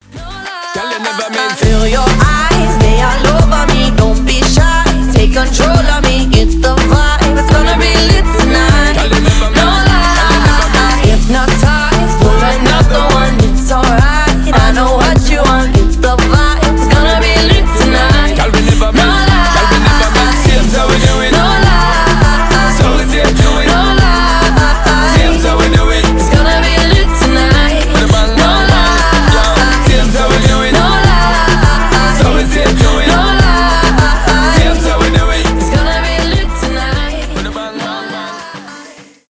дуэт
dancehall
tropical house , заводные